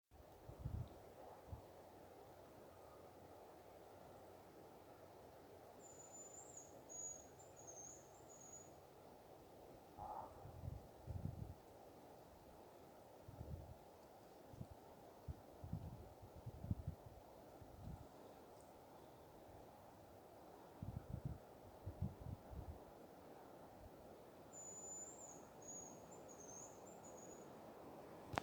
Hazel Grouse, Bonasa bonasia
StatusSinging male in breeding season